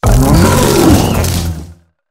wochien_ambient.ogg